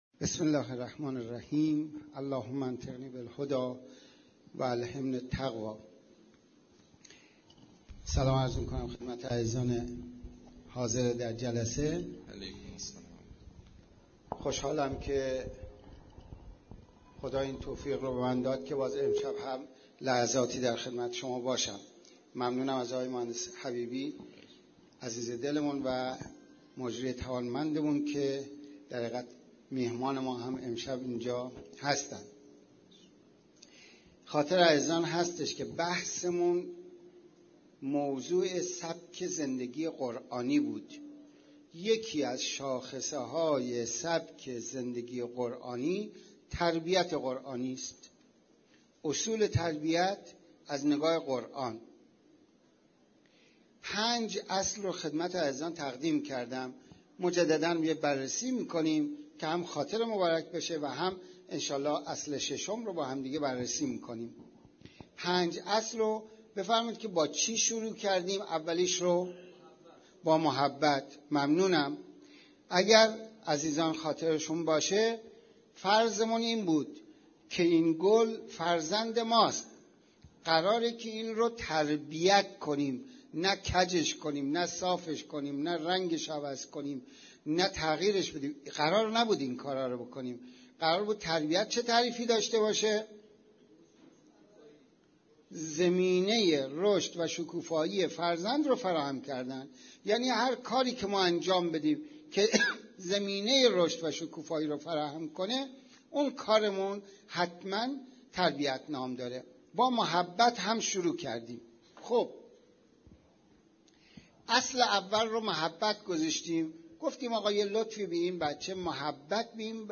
گزارش صوتی دویست و یکمین کرسی تلاوت و تفسیر قرآن کریم - پایگاه اطلاع رسانی ضیافت نور